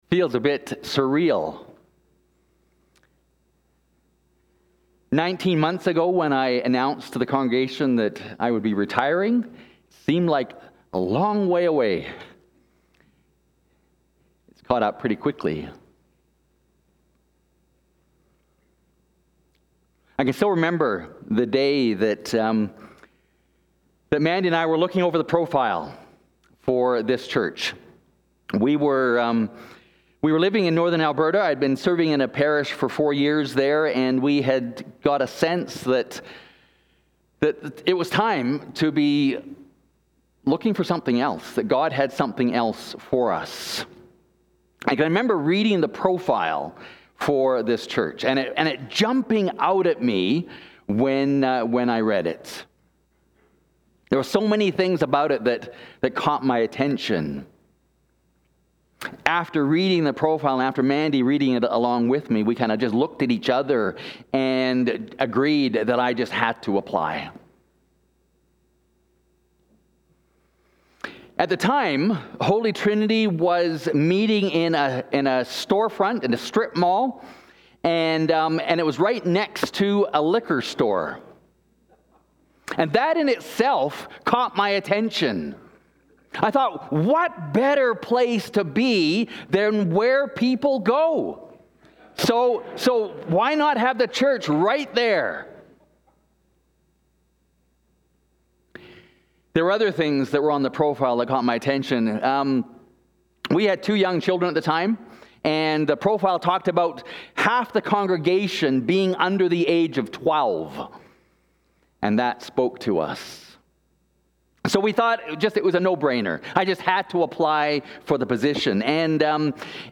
Holy Trinity Anglican Church (Calgary)